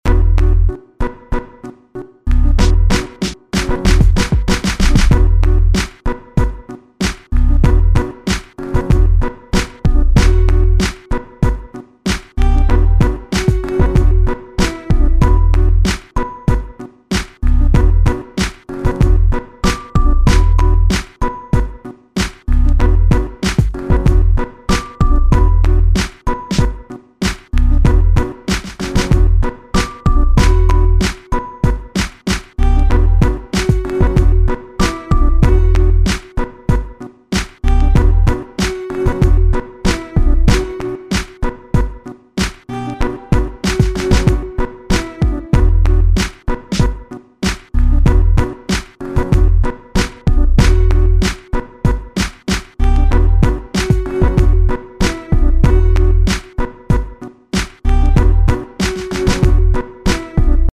more grime